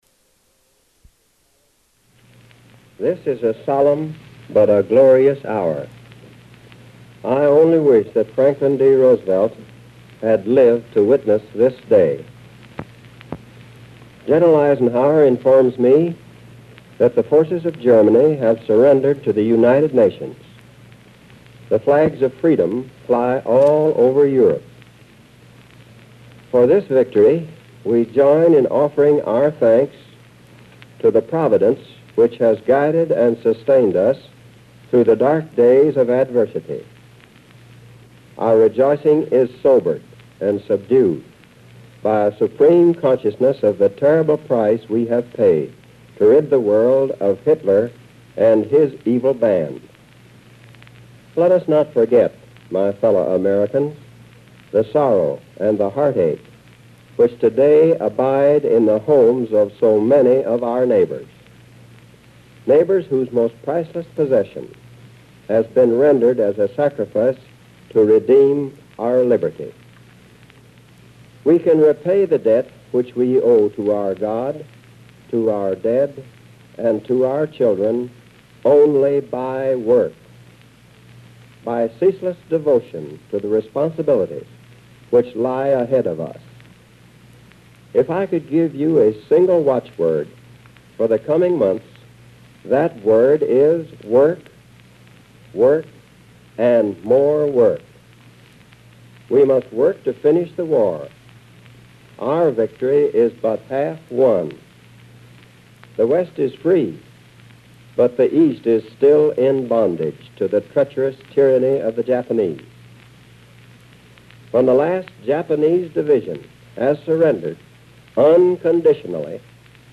History  Speeches
Delivered on May 8, 1945.